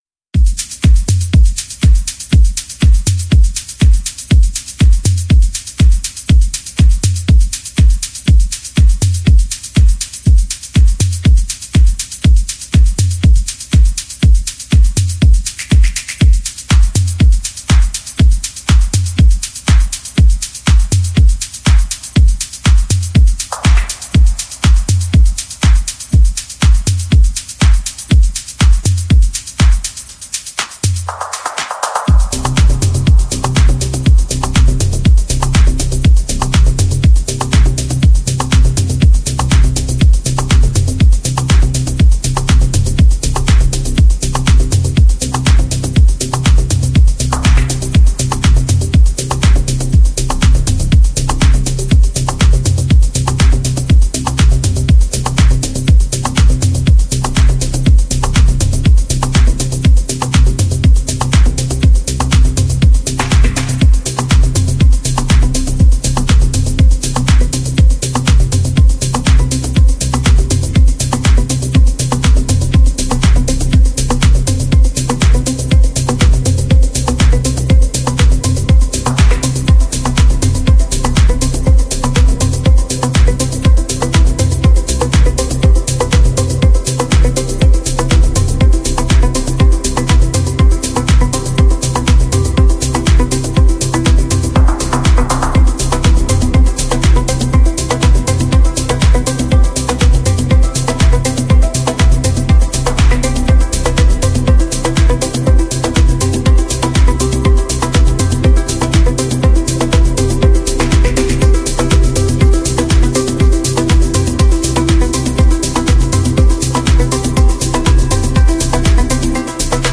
Progressive House